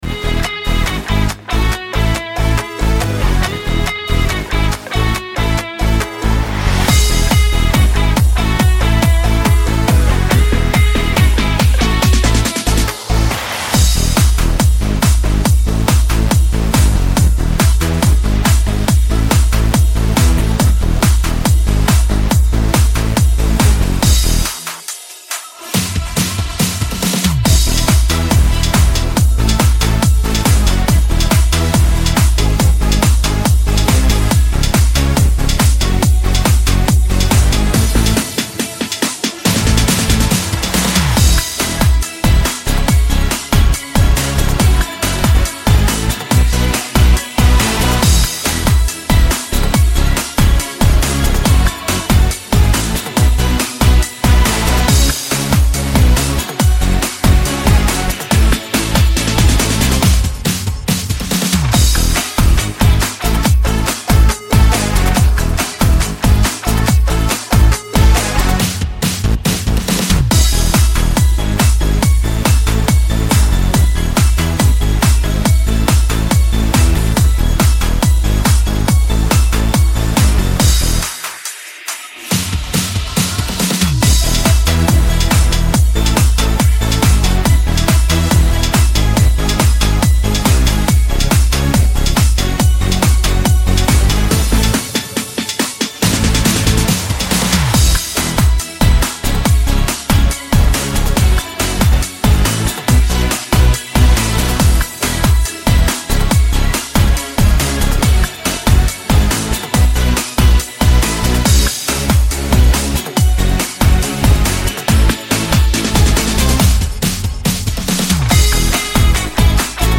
Слушать минус